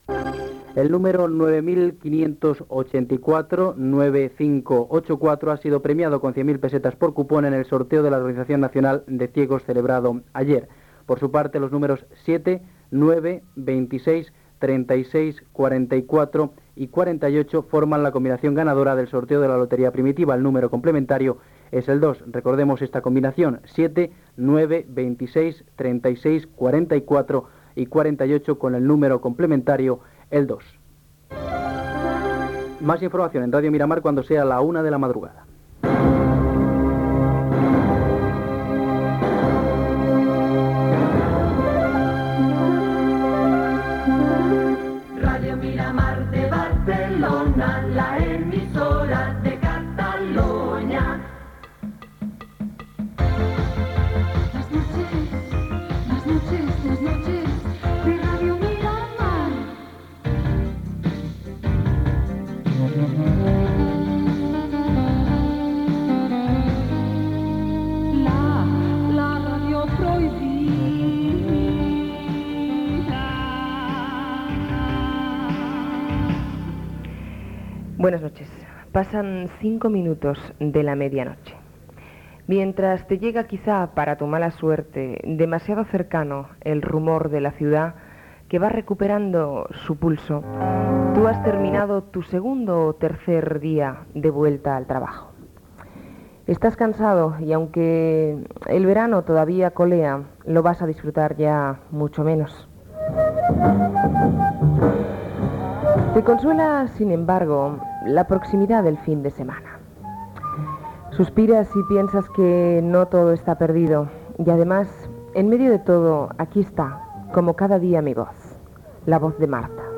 indicatiu de l'emissora
careta del programa